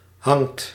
Ääntäminen
Ääntäminen Tuntematon aksentti: IPA: /ɦɑŋt/ Haettu sana löytyi näillä lähdekielillä: hollanti Käännöksiä ei löytynyt valitulle kohdekielelle.